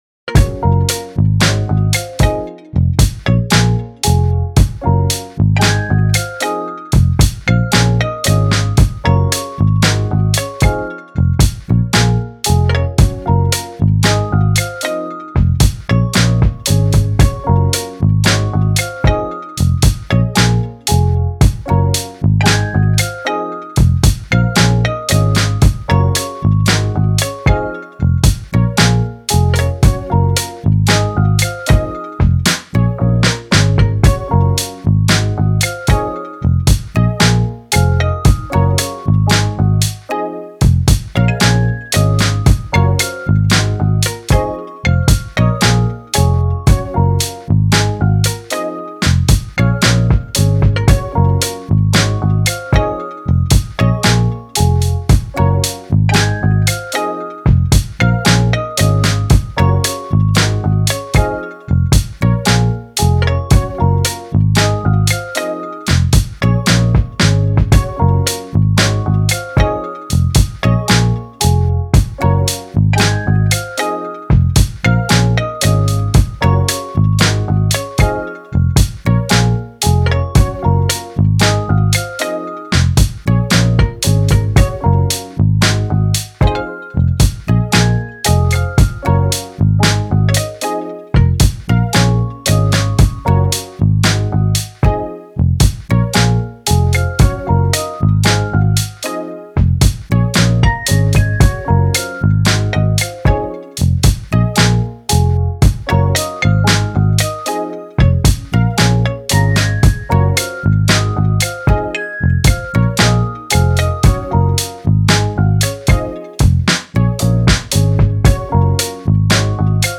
カフェミュージック チル・穏やか
明るい・ポップ